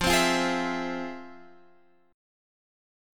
FmM7 chord